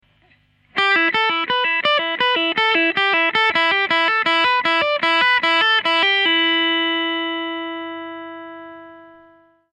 One of the easiest ways to utilise an open string is to use the E minor pentatonic scale on the E (1st string) to play hammer on and pull off licks.
Open String E Minor Pentatonic Lick
openstring_eminorpentatonic.mp3